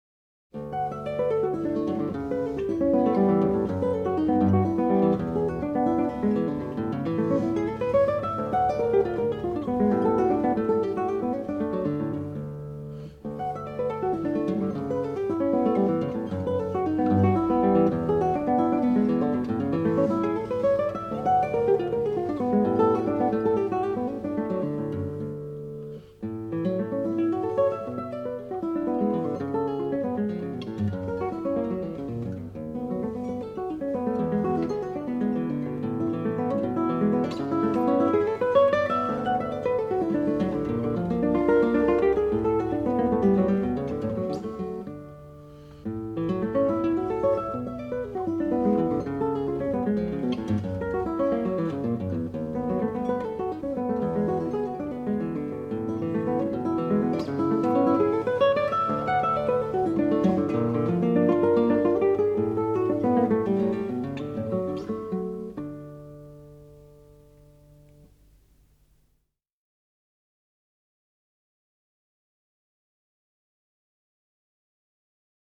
Gitarrenmusik des 19. & 20. Jh.